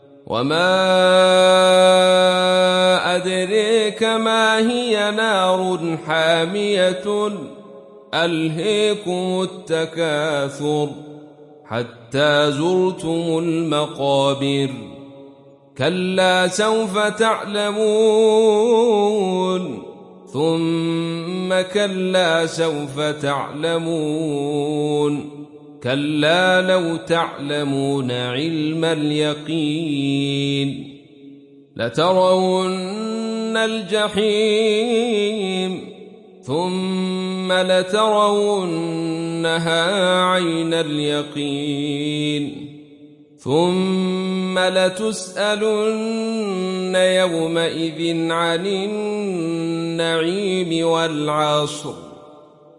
Surah At Takathur Download mp3 Abdul Rashid Sufi Riwayat Khalaf from Hamza, Download Quran and listen mp3 full direct links